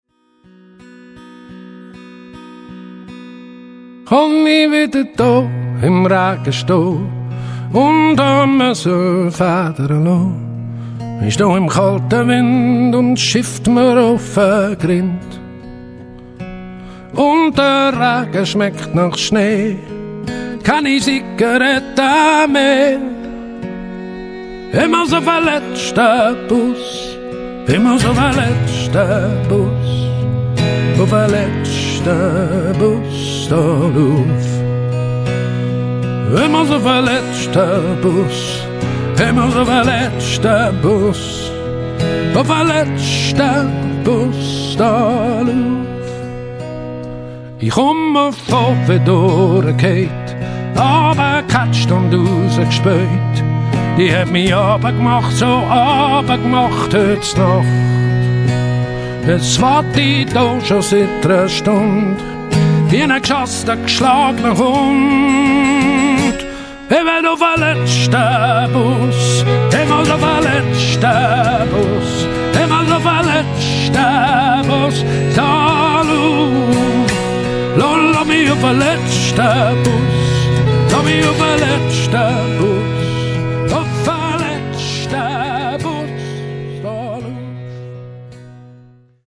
Eurostudio Wildenstein, Bubendorf / Schweiz.